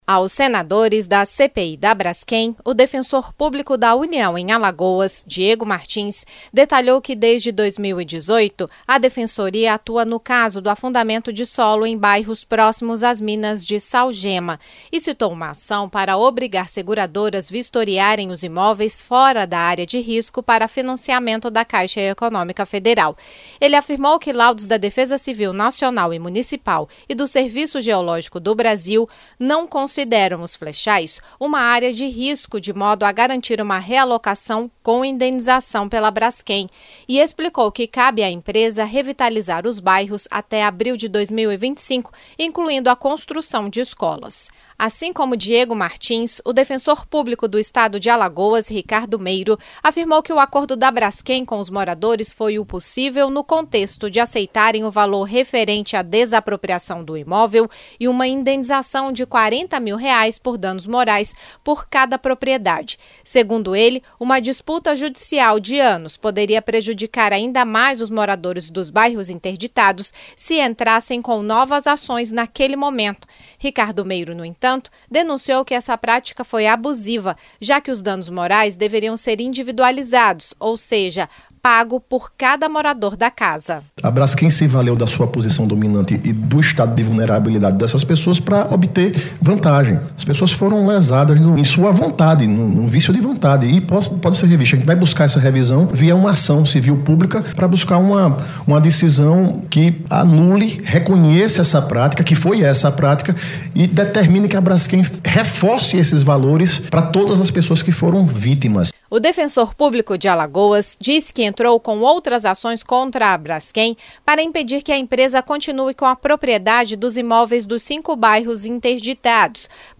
Em depoimento à CPI da Braskem, o Defensor Público da União em Alagoas, Diego Martins, e o Defensor Público do Estado de Alagoas, Ricardo Meiro, disseram que pode ser revisto o acordo da empresa com os moradores obrigados a deixarem suas casas em Maceió. Eles citaram que há cláusulas abusivas, como o pagamento único da indenização por dano moral por cada imóvel e não por pessoa que morava no local.